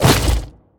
biter-roar-behemoth-1.ogg